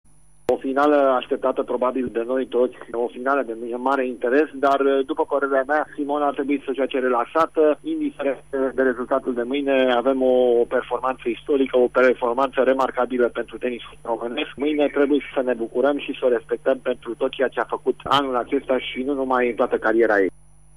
antrenor de tenis se bucura de victoria de astazi a Simonei: